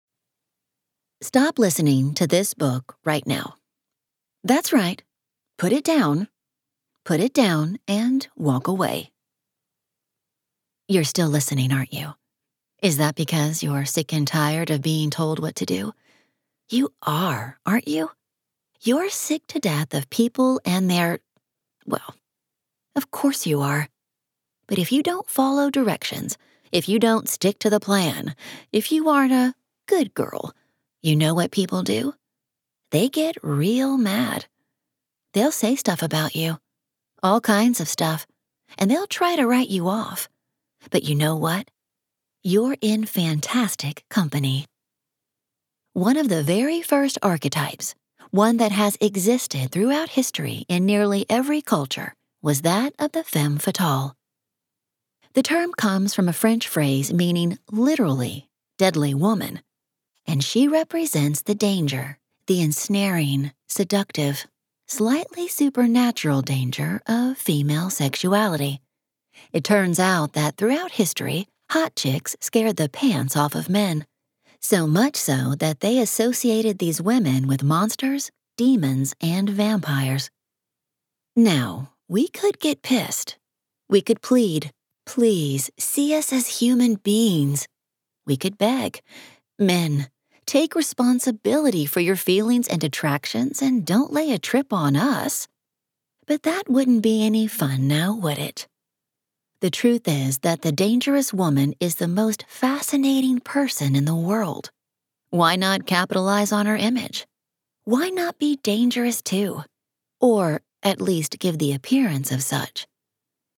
With a voice that’s warm and strong, with an underlying vulnerability that can easily turn snarky when needed, her versatility and adeptness with accents allow her to work in many genres.